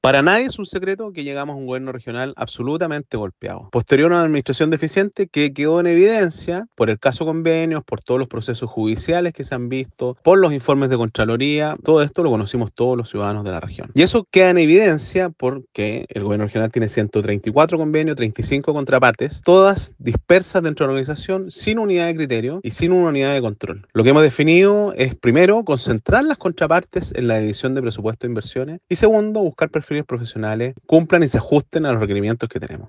Al respecto, el gobernador del Bío Bío, Sergio Giacaman, señaló que todos saben que recibió el GORE golpeado y debió hacer modificaciones.
cuna-giacaman.mp3